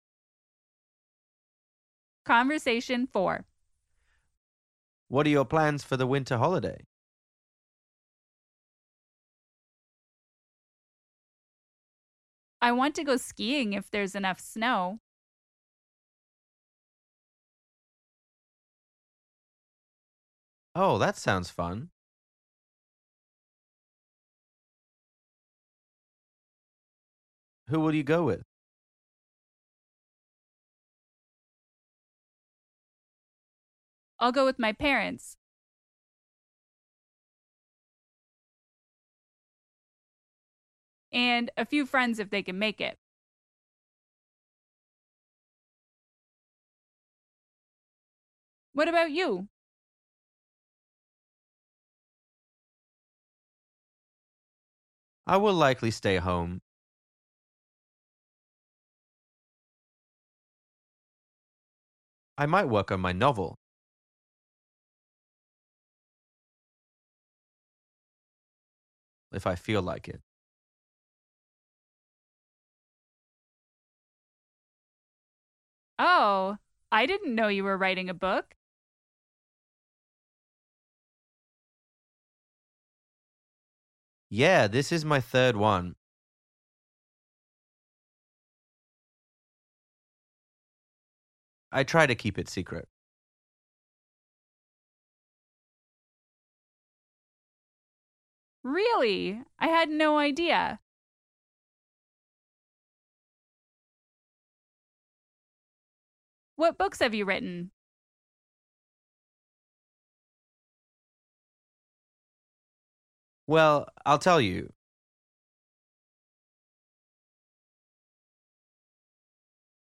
TRAIN - Conversation 4 - What are your plans for the winter holiday?